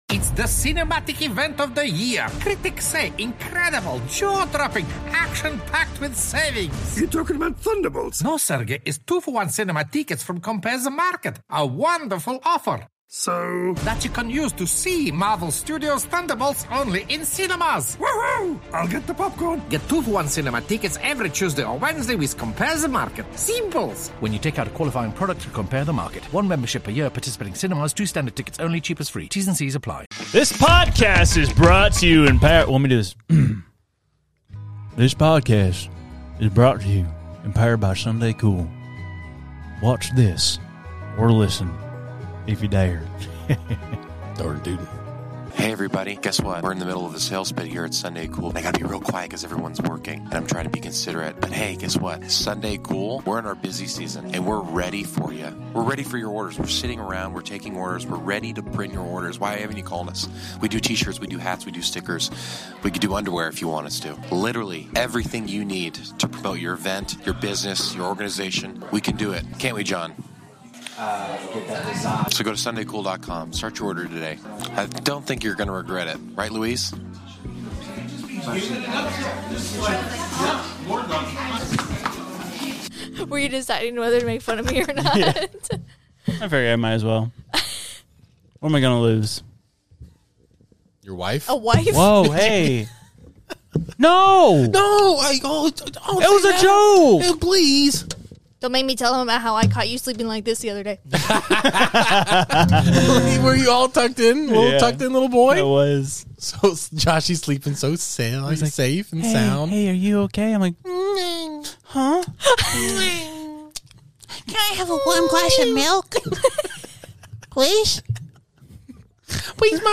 In today's episode, get ready to tap your boots as we sing a heartfelt country song, and stick around as we delve into more extraterrestrial mysteries. We also share our personal horror stories of the worst jobs we've ever had, from selling newspapers to getting robbed while scrubbing bugs off cars. And if that's not enough to get your blood pumping, just wait until you hear the spine-tingling sound of a death whistle.